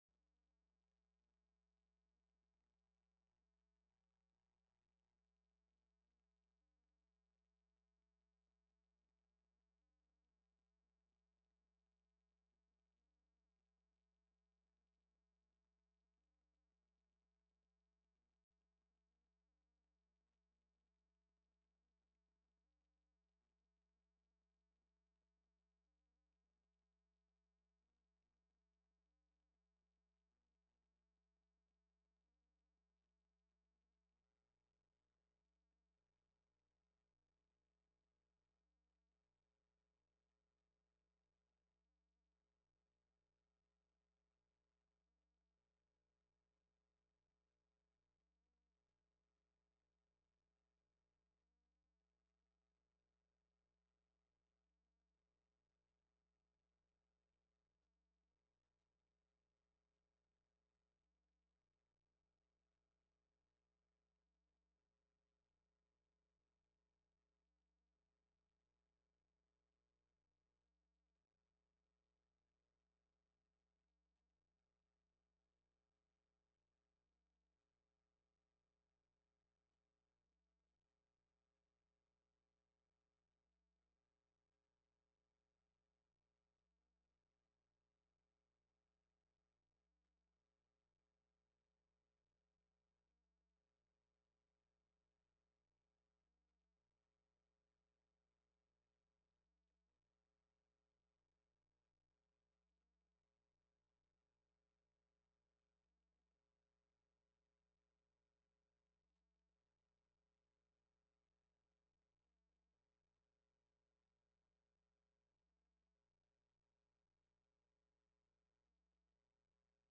**The sound kicks in at the 3:18 mark.**